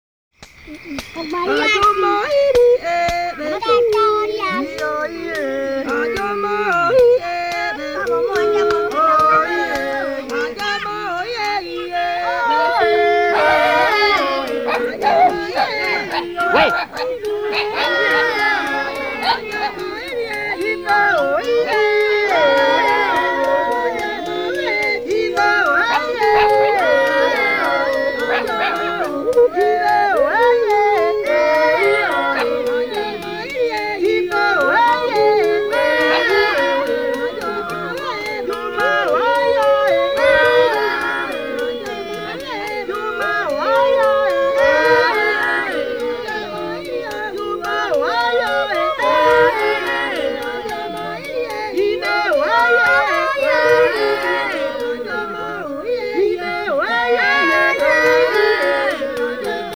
Rhythm stick
As regards sound, a clear and high pitch is preferred, supplementing the rest of the percussion, or to provide a rhythmical pulse for group singing. Generally the pattern is the same throughout the entire song and sometimes it is no more than a beat on each count.
This type of instrument is used in recordings of our sound archives made with the Congolese peoples mentioned hereafter where it appears with the following vernacular names: